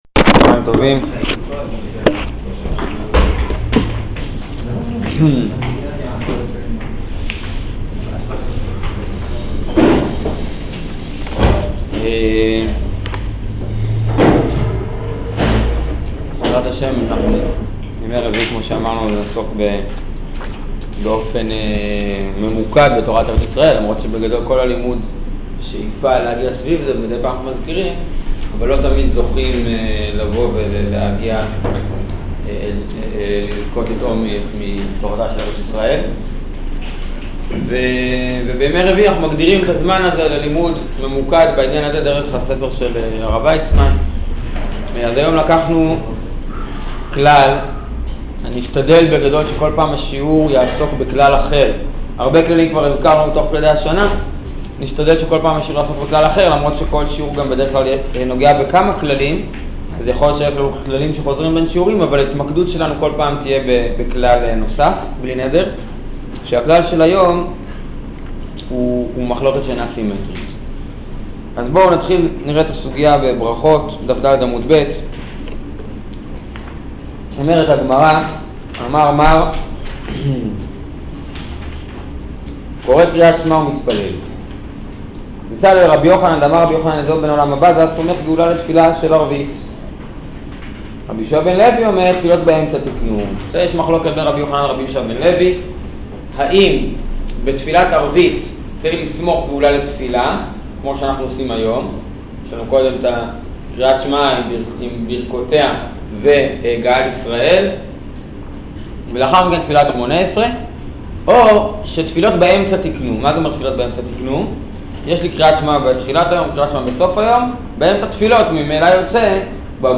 שיעור סמיכת גאולה לתפילה